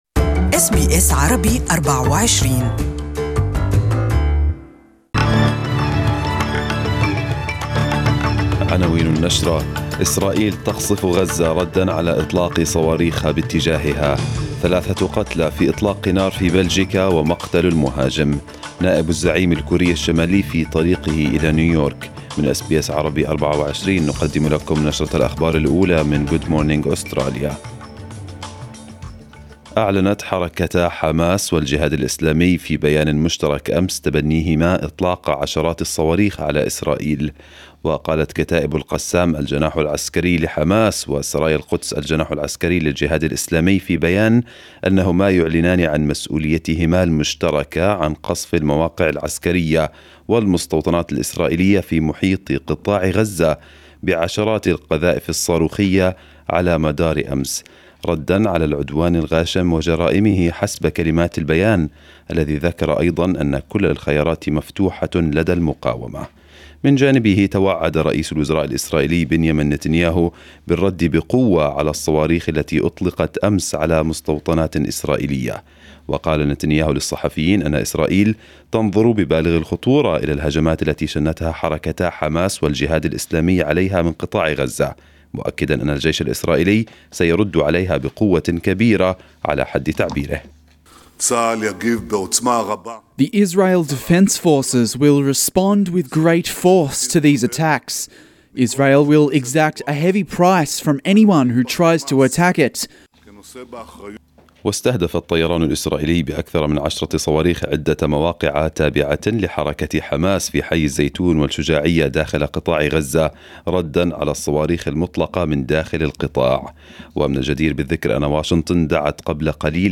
Arabic News Bulletin 30/05/2018